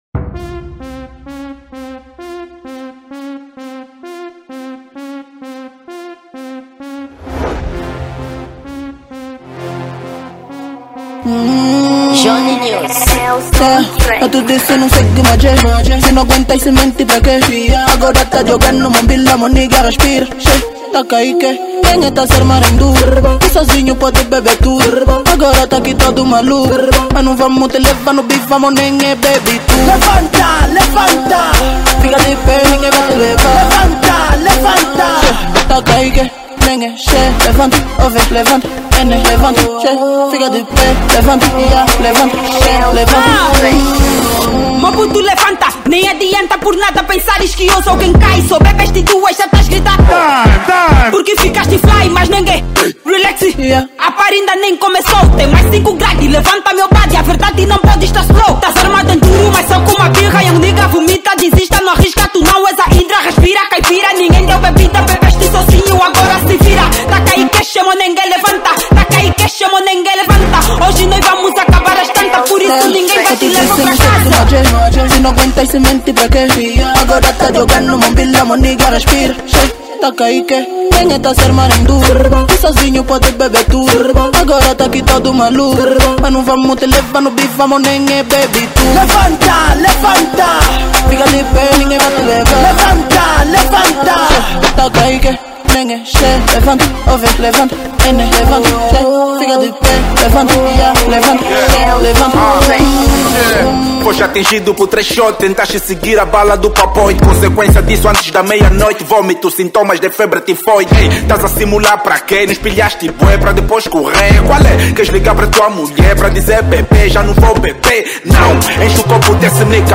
Rap
Gênero: Drill